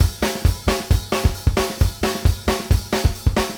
OPNHAT GRO-R.wav